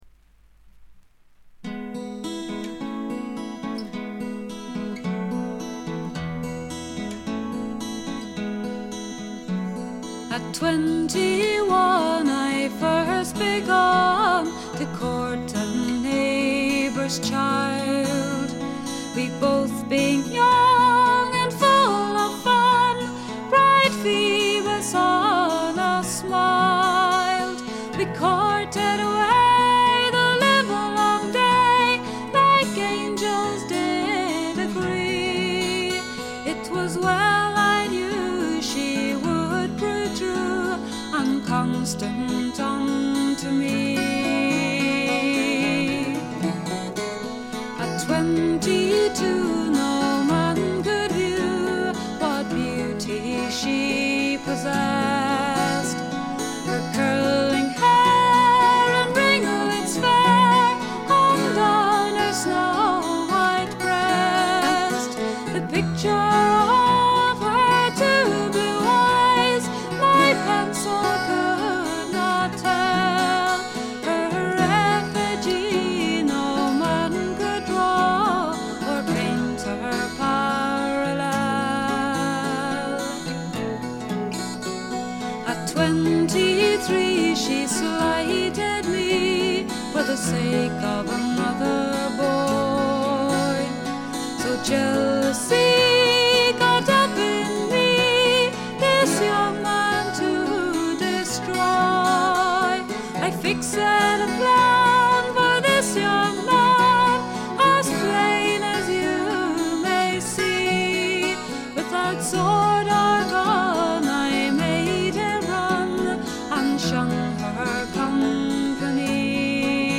アイルランドの女性シンガー・ソングライターでトラッドと自作が半々という構成。
天性のとても美しい声の持ち主であるとともに、歌唱力がまた素晴らしいので神々しいまでの世界を構築しています。
試聴曲は現品からの取り込み音源です。
Bouzouki
Recorder